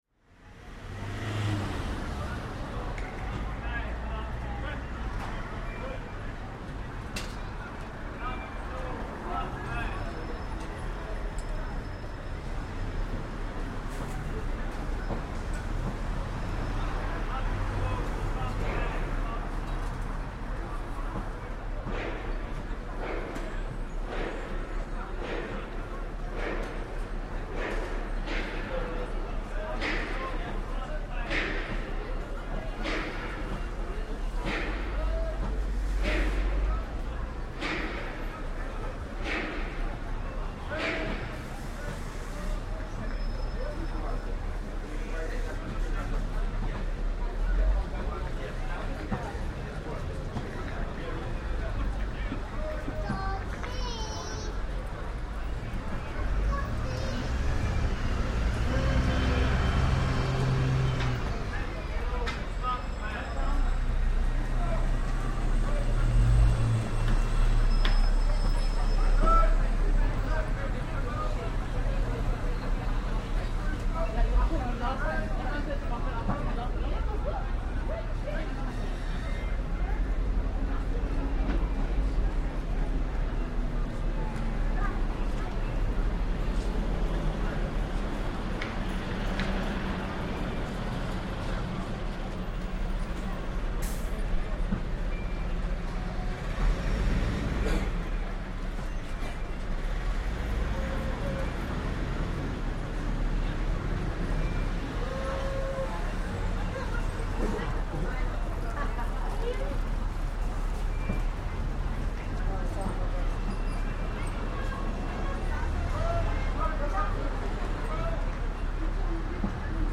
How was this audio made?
Outside Dalston Kingsland tube station Field recording from the London Underground by London Sound Survey.